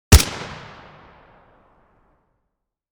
This Category contains Sniper Sound Effects including some of major Precision Rifle Variants used by Snipers from around the World!
Dragunov-sniper-rifle-single-shot.mp3